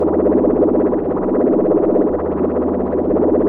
arcaneloop02.wav